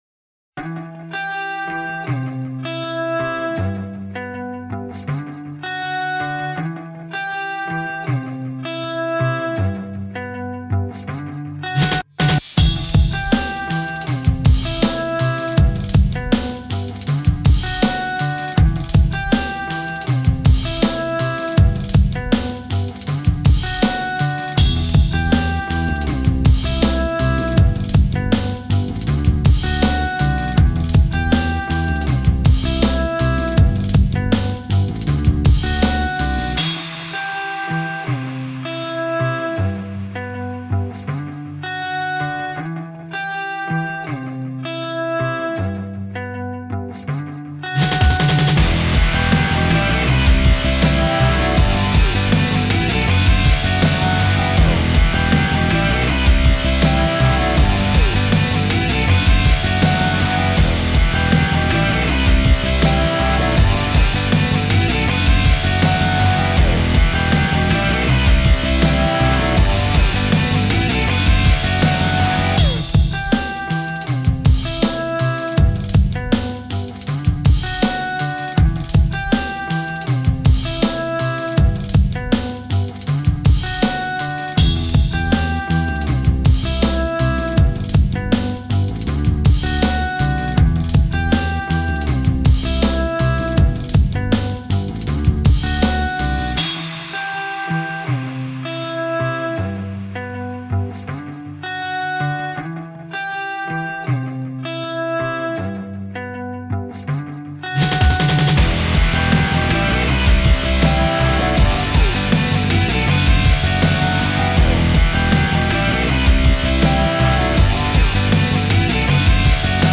K-Pop Instrumental